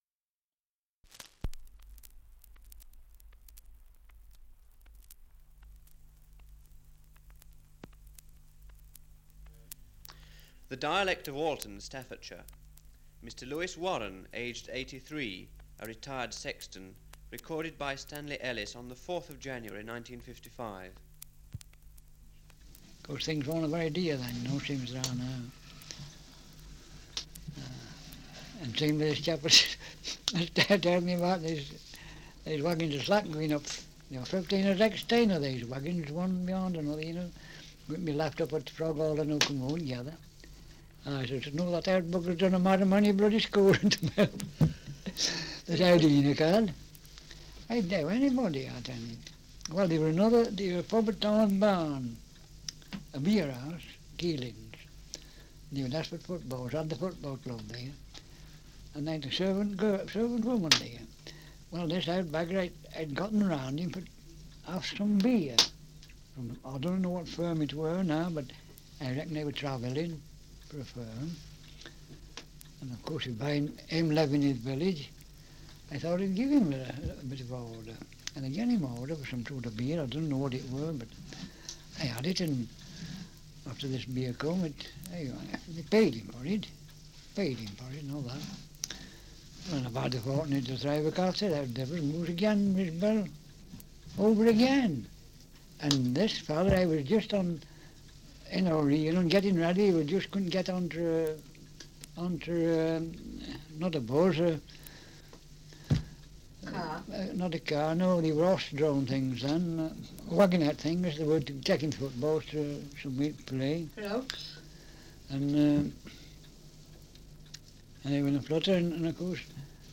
Survey of English Dialects recording in Alton, Staffordshire
78 r.p.m., cellulose nitrate on aluminium